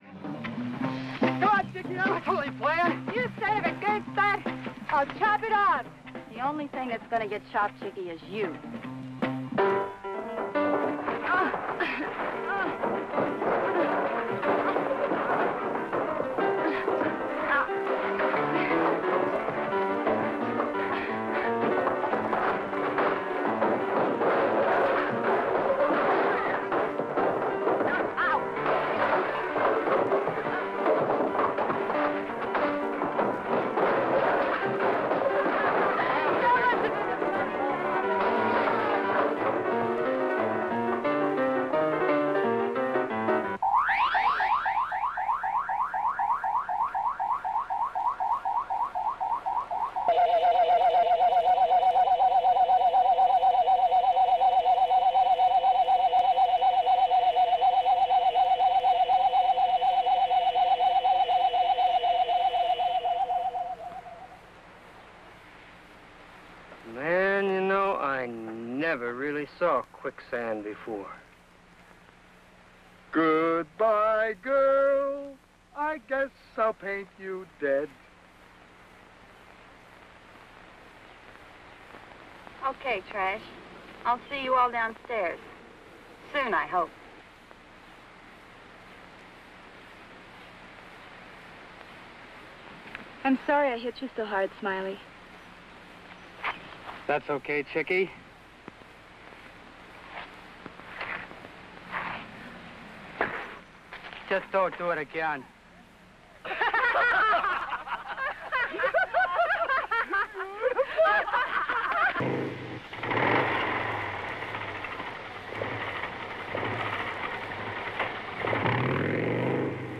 Sorry about the low quality.